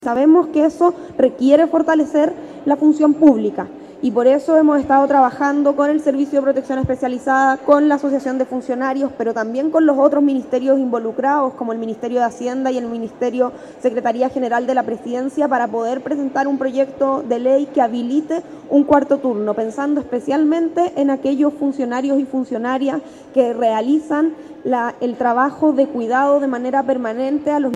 La cuenta pública de la secretaría de estado se extendió por cerca de una hora y contó con la presencia de 700 personas, entre autoridades, delegaciones de comunas y estudiantes.